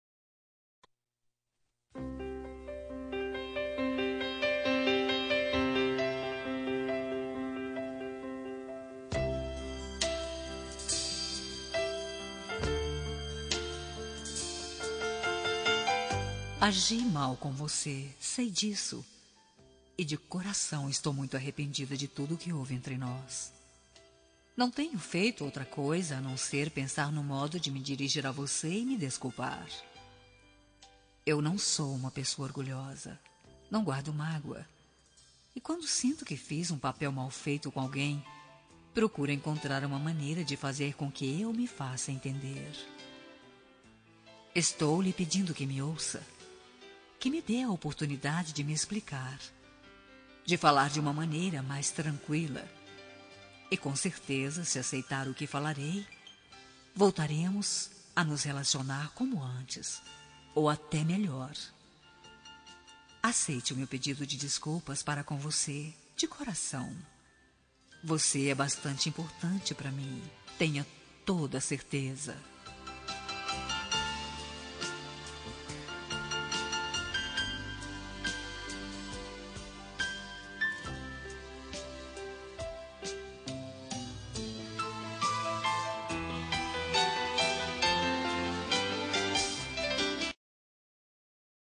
Telemensagem de Desculpa – Neutra – Voz Feminina – Cód: 70001 – Forte